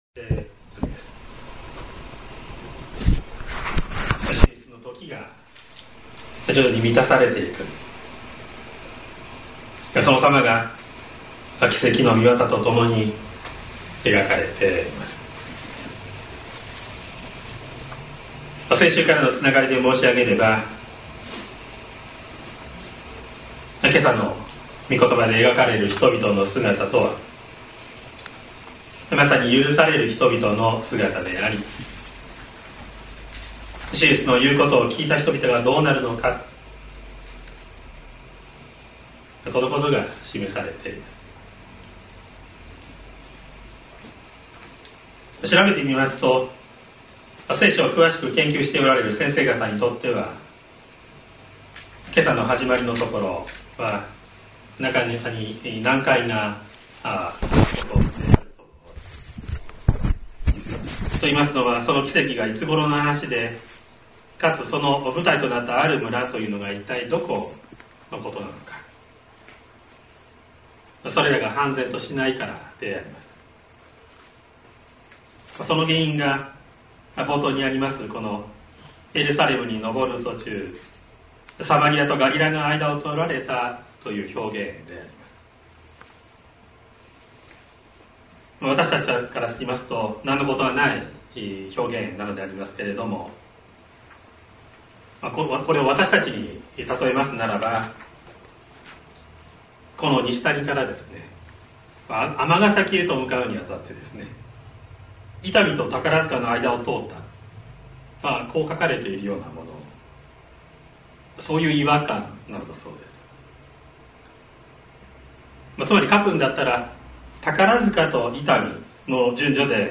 2025年04月06日朝の礼拝「いやしと救い」西谷教会
音声ファイル 礼拝説教を録音した音声ファイルを公開しています。